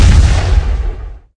qiukui hit.mp3